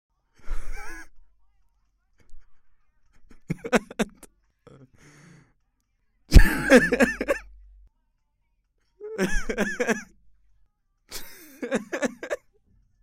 Risada Grave.R
Tag: UAM 快乐 幽默 麦克风